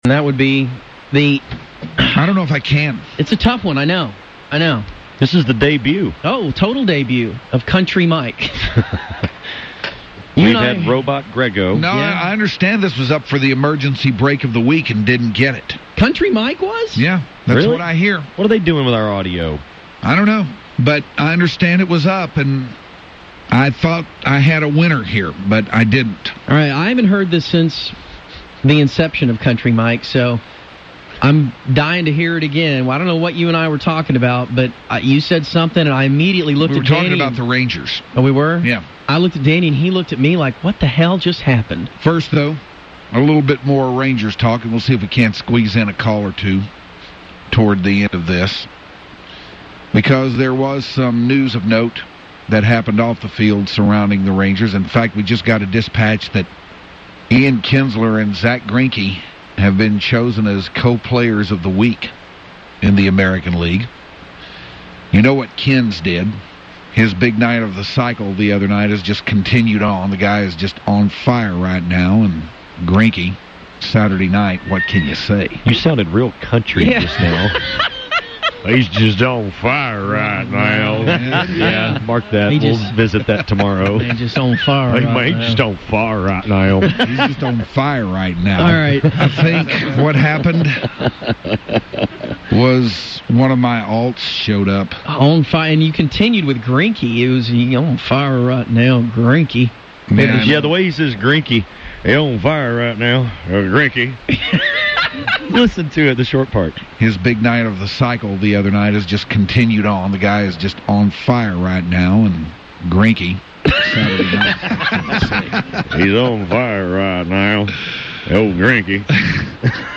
Funny Caller Laugh
The Hardline has fun with real audio.